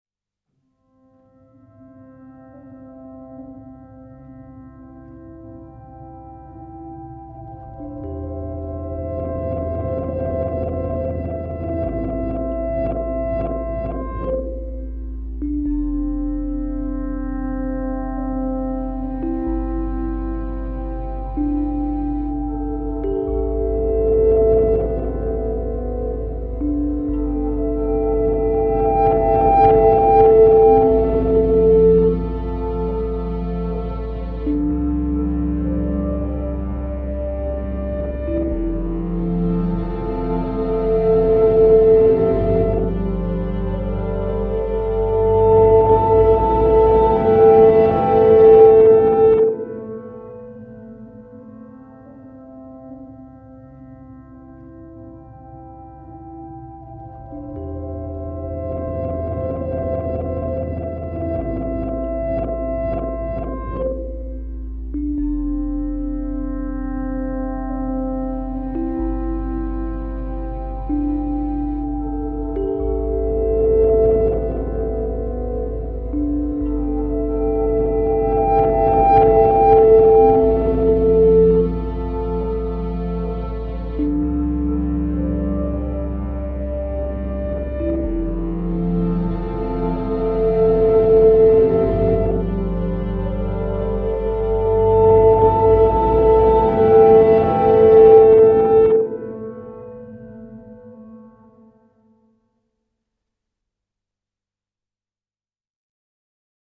Textural layers, tones and natural atmosphere.